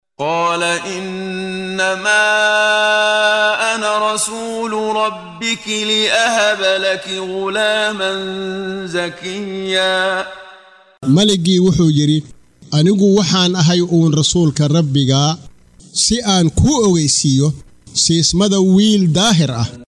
Waa Akhrin Codeed Af Soomaali ah ee Macaanida Suuradda Maryam oo u kala Qaybsan Aayado ahaan ayna la Socoto Akhrinta Qaariga Sheekh Muxammad Siddiiq Al-Manshaawi.